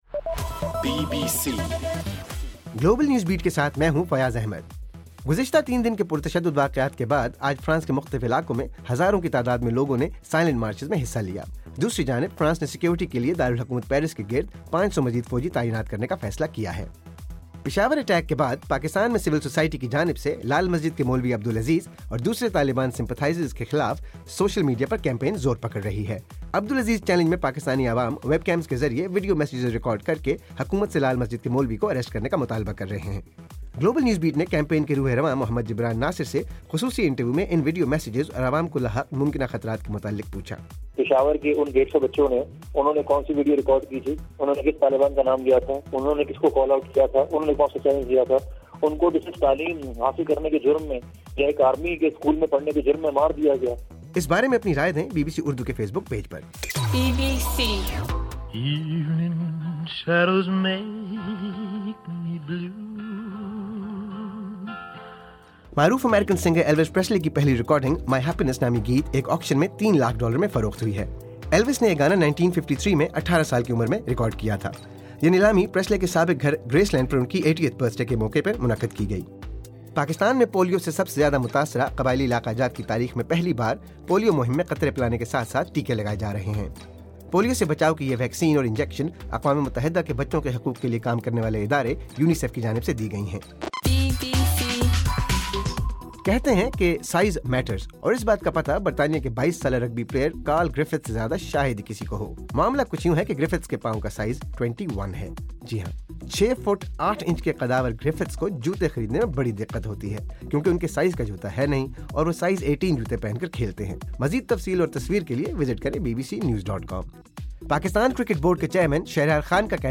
جنوری 11: صبح 1 بجے کا گلوبل نیوز بیٹ بُلیٹن